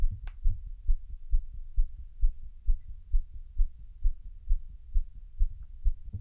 Ленточный микрофон "АПЛ" (Алюминиевая-Поталевая-Лента) лента 0,2 микрона.
//Когда попробовал с близи сердце записать,где то 1 см от микрофона амплитуда тоже была до -12 дБ на графике)) Вот микрофон в ветрозащите даже... Гаин на всю вправо. Вложения Сердце1смВчехле.wav Сердце1смВчехле.wav 802,2 KB · Просмотры: 122